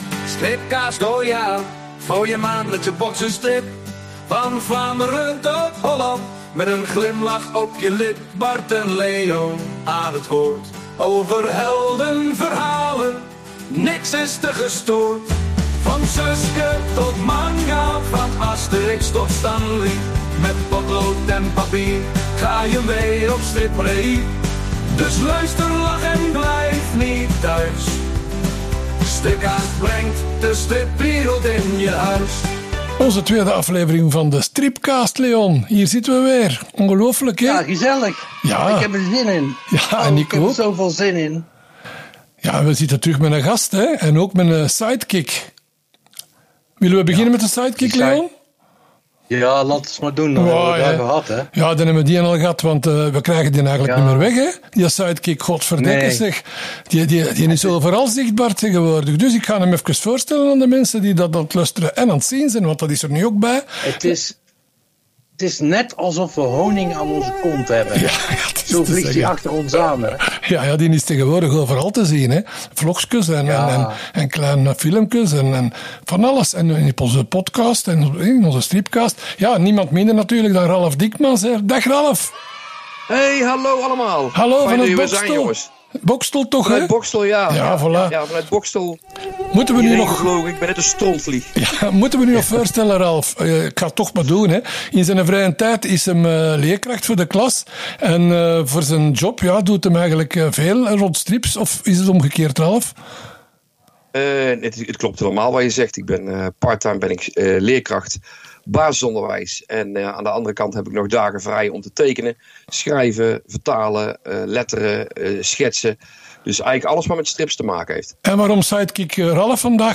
Onze striphelden uit België en Nederland zijn weer samengekomen voor een gloednieuwe aflevering van de Stripkaast Podcast! En jawel… dit keer schuift er een mysterieuze gast aan tafel.